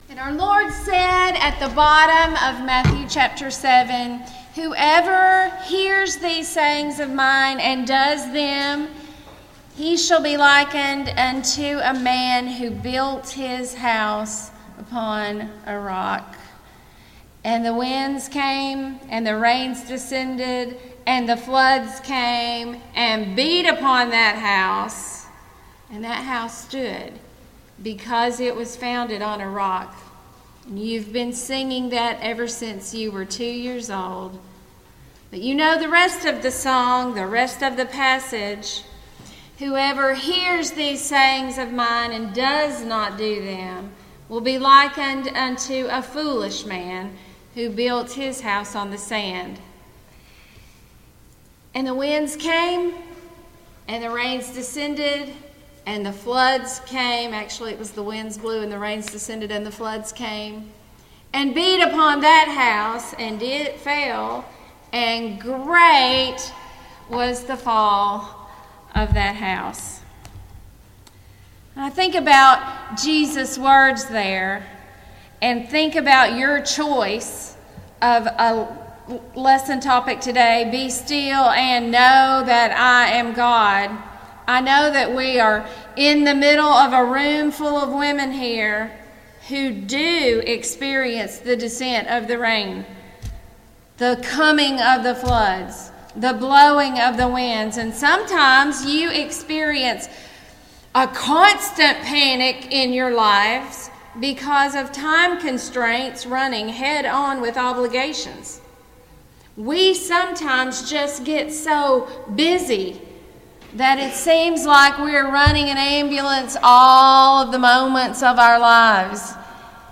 Passage: Psalm 46:1-7 Service Type: Ladies' Day